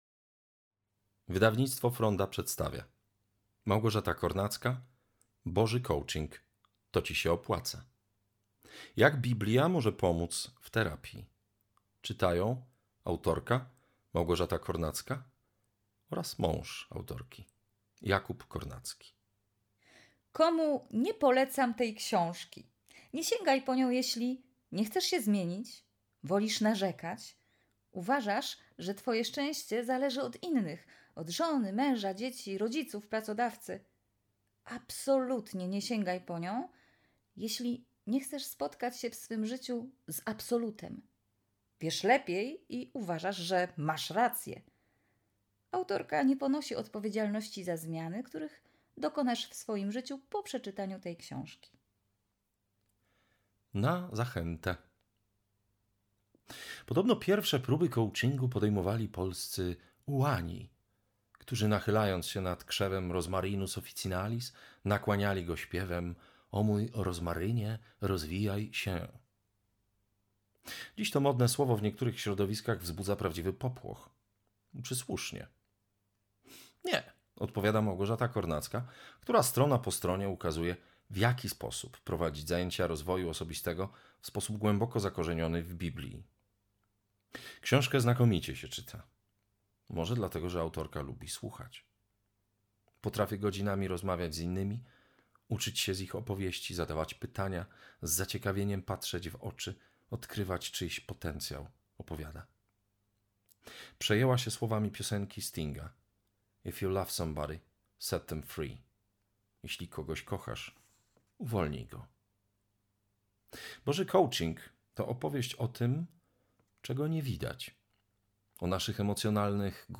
Boży coaching – Audiobook